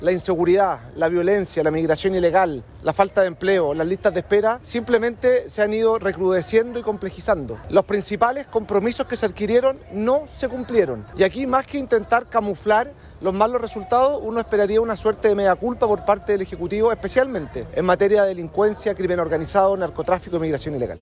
En esa línea, el secretario general de UDI, Juan Antonio Coloma, habló de inseguridad, listas de espera, y desempleo.